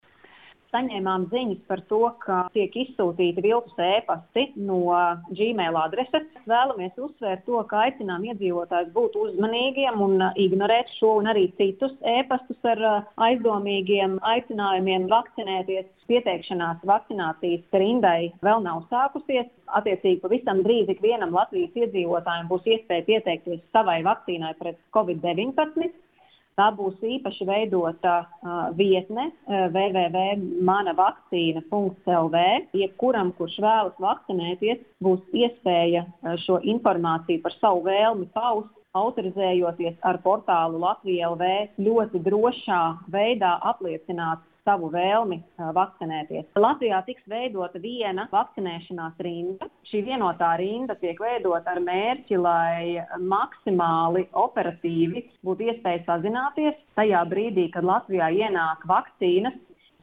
Radio Skonto Ziņās par viltus aicinājumiem vakcinēties